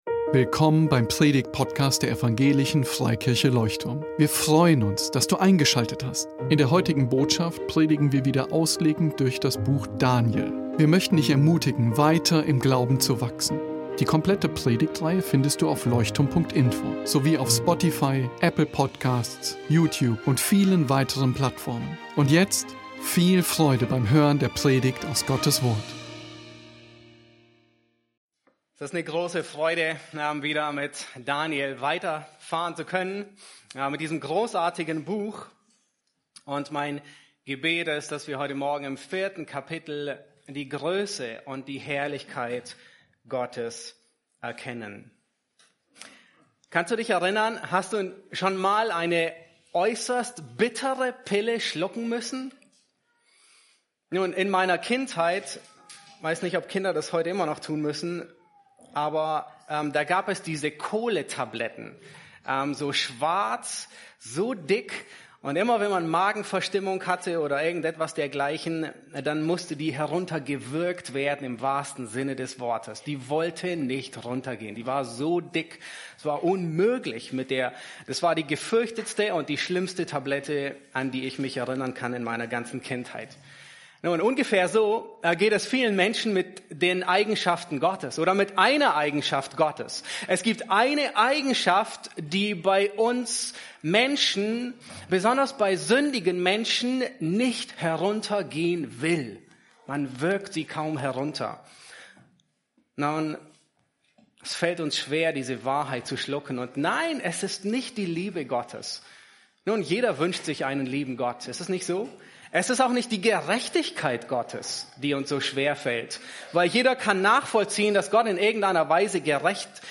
Daniel 4 | Gottes Souveränität: eine bittere Pille, jedoch süßer als honig ~ Leuchtturm Predigtpodcast Podcast